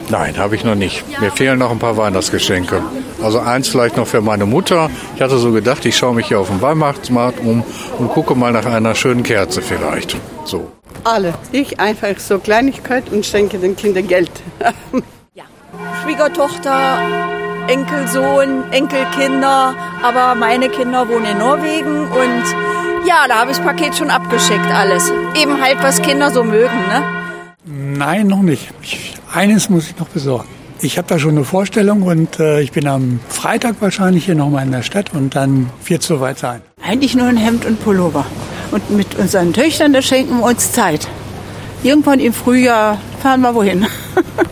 Umfrage: Haben Sie schon alle Weihnachtsgeschenke beisammen